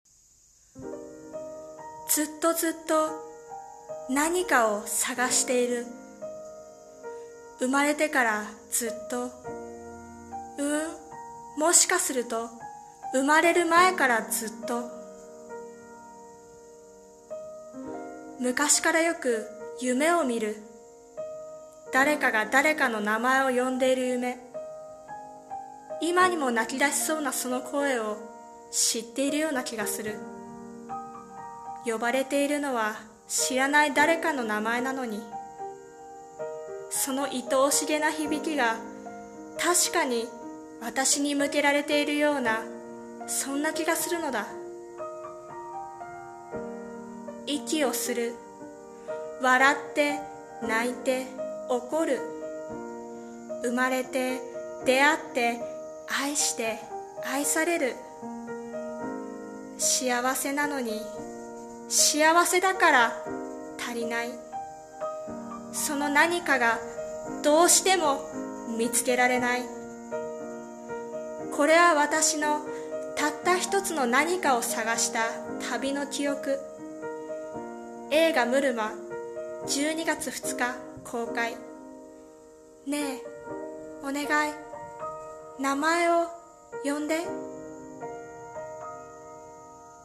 さんの投稿した曲一覧 を表示 映画予告風台本「ムルマ」